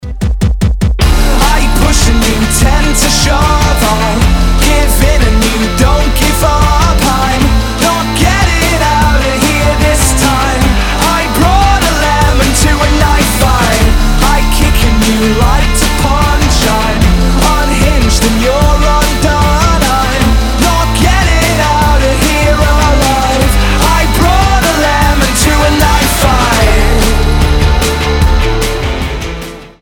• Качество: 320, Stereo
мужской вокал
Alternative Rock
indie rock
бодрые